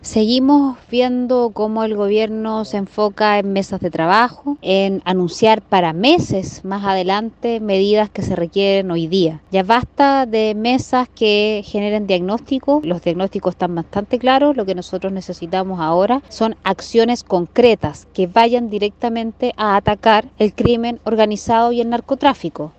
Diputada Flores se refiere al Plan de Seguridad Pública